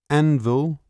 a_anvil.wav